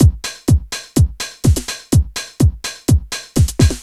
Index of /musicradar/retro-house-samples/Drum Loops
Beat 17 Full (125BPM).wav